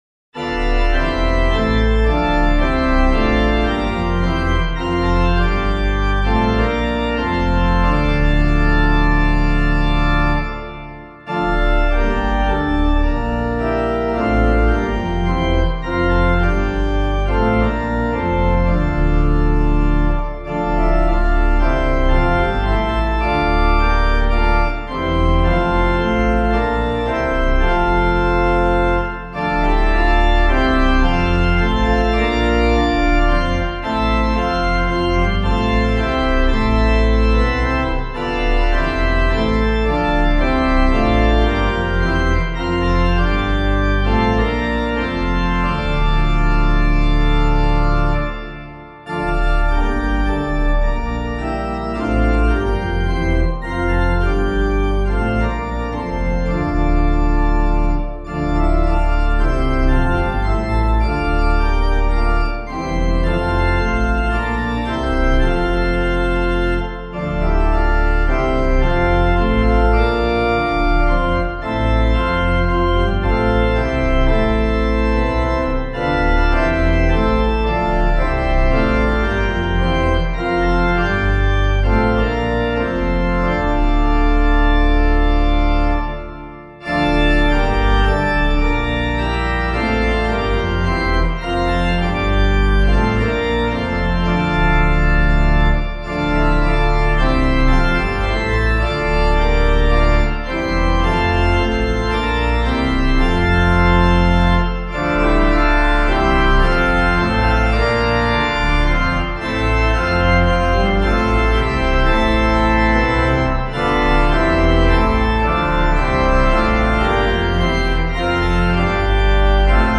Meter:    76.76 D
Composer:    English melody;
organ